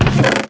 chestopen.ogg